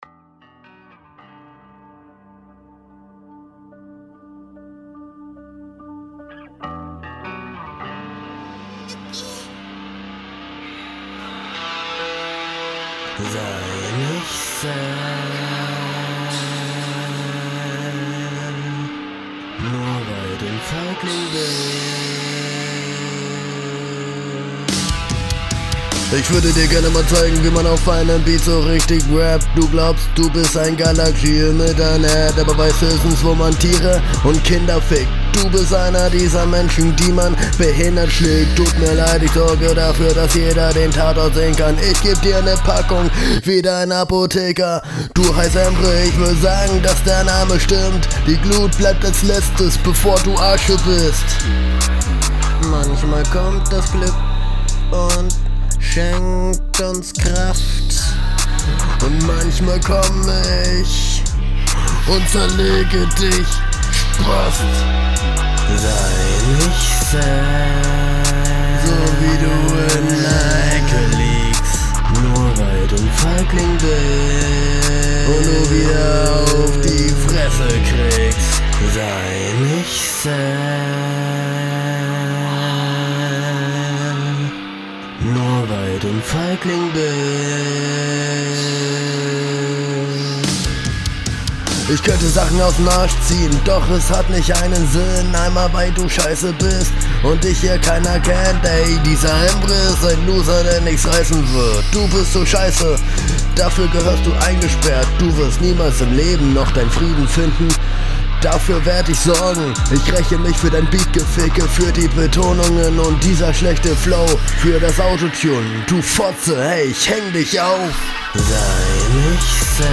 guck dir ma tutorials zu gesangsspuren recorden an; da packt man am besten mehrere spuren …
Der „Gesang“ wars gar nicht.